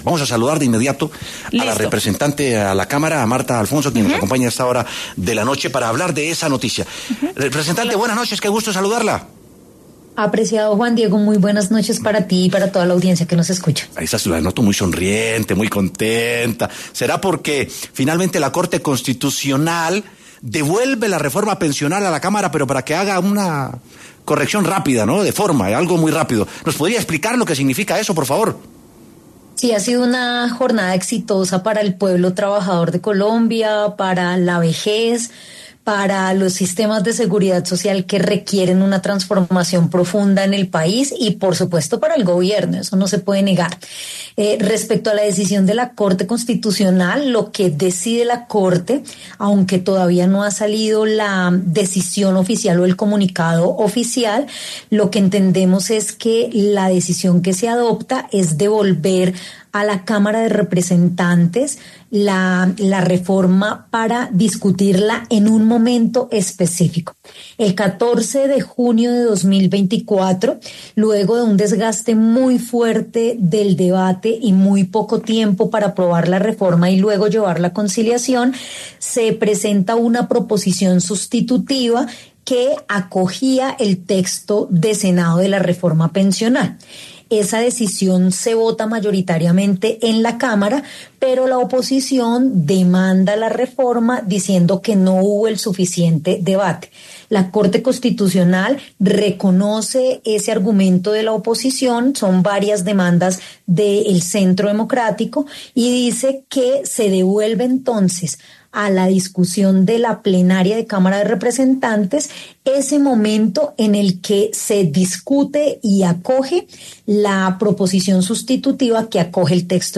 La representante a la Cámara, Martha Alfonso, conversó con W Sin Carreta sobre la reforma pensional, el paso a seguir con el proyecto y más detalles de su implementación.
Para hablar sobre estos temas, pasó por los micrófonos de W Sin Carreta la representante a la Cámara, Martha Alfonso, del Pacto Histórico.